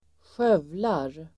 Uttal: [²sj'öv:lar]